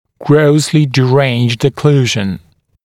[‘grəuslɪ dɪ’reɪnʤd ə’kluːʒ(ə)n][‘гроусли ди’рэйнджд э’клу:ж(э)н]окклюзия со значительными нарушениями